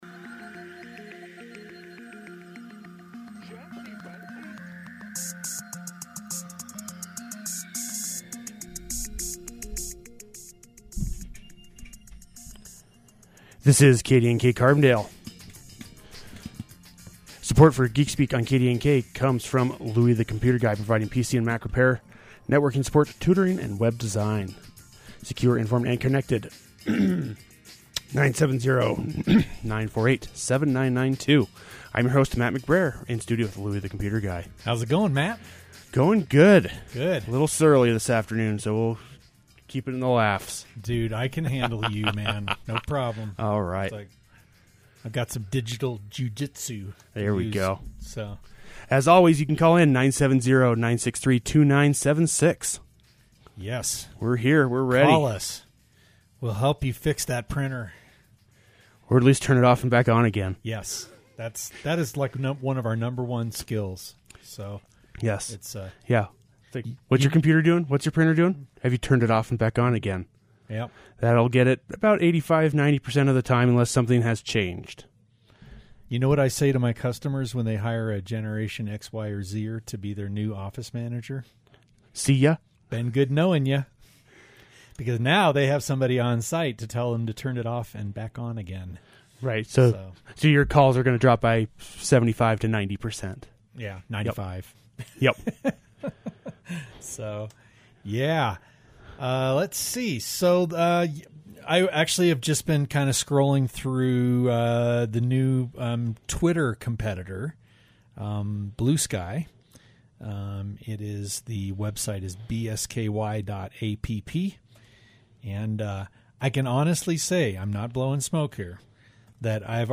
A beloved call-in tech talk show